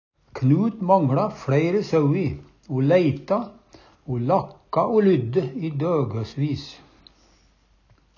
lakke o ly - Numedalsmål (en-US)